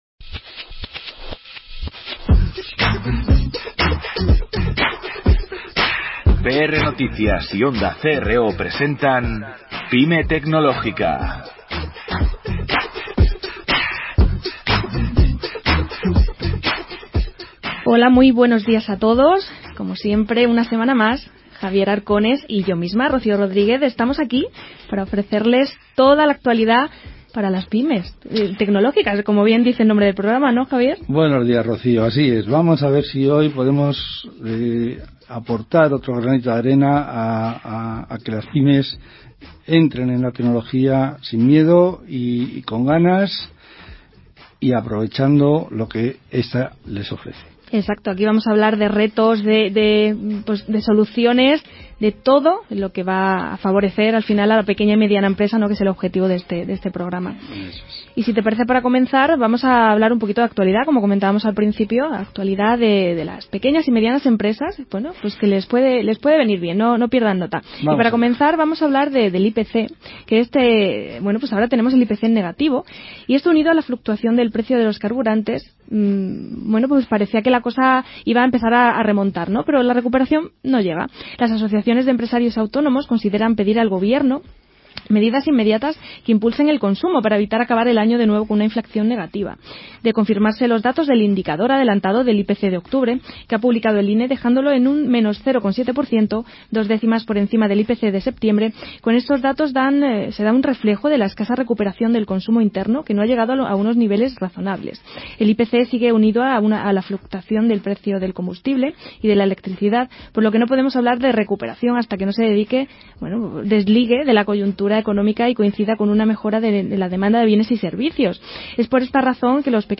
Lo analizamos con nuestros tres expertos.